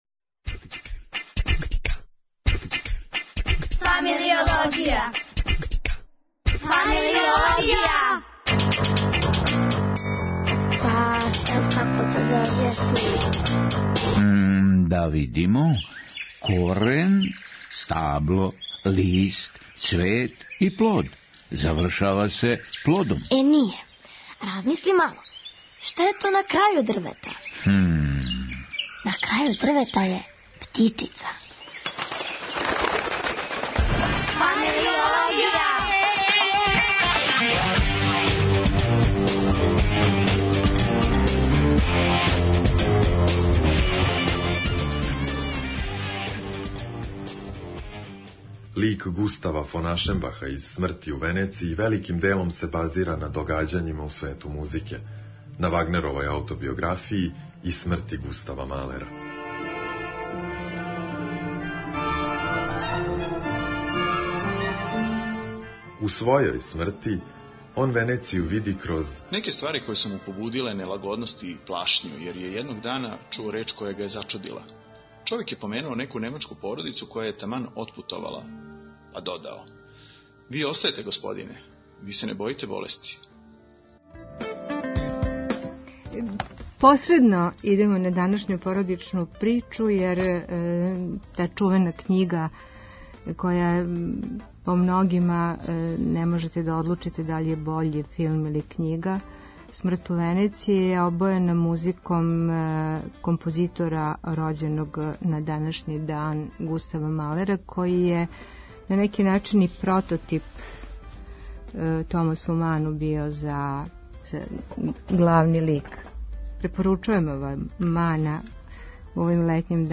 Он нам је гост.